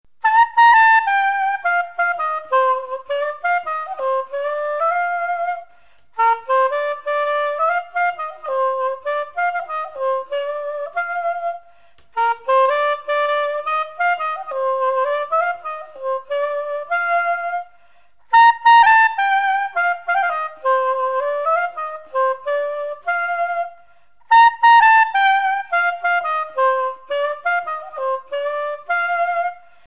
De vent-fusta
Tot i que és un instrument de metall, com que el so es produeix a través de la vibració d'una llengüeta de canya, que hi ha a l'embocadura, se l'inclou dins el grup d'instruments de vent-fusta.
saxòfon
saxo.mp3